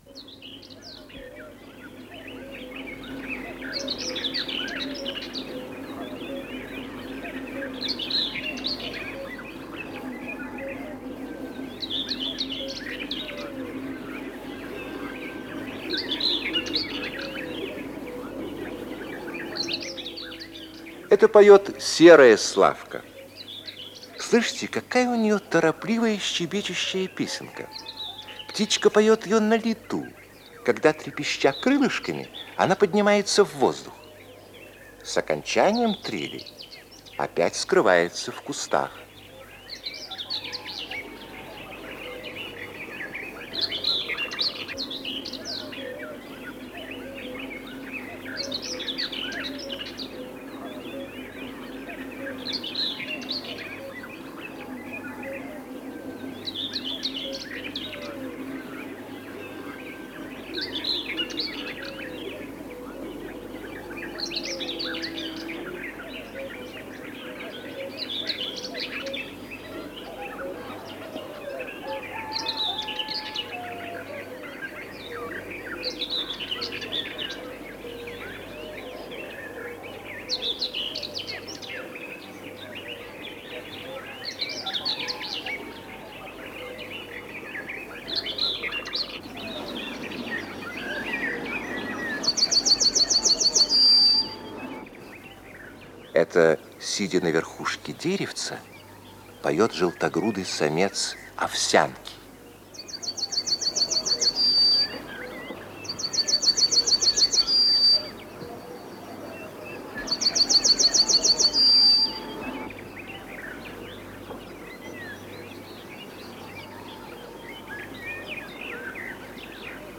П-01603 — Голоса птиц в природе — Ретро-архив Аудио
Исполнитель: читает Владимир Герцик